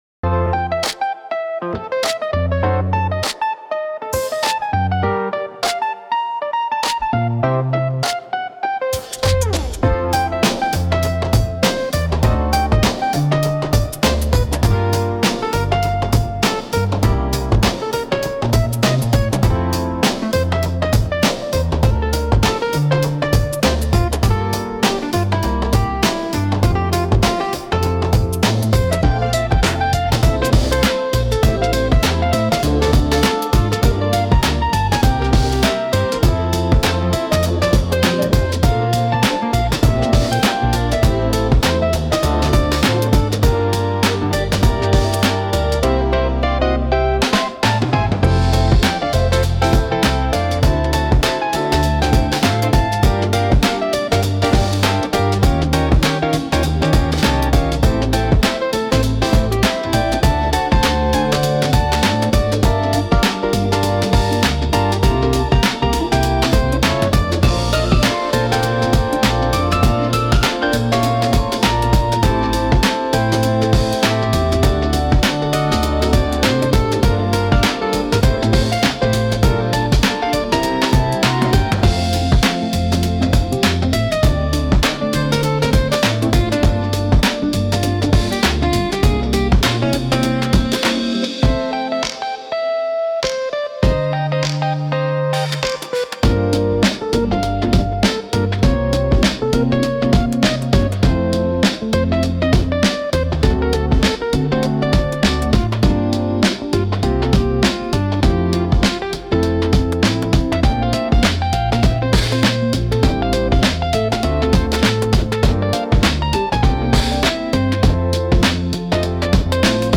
ケアフリーチルポップ・ボーカル無し
明るい おしゃれ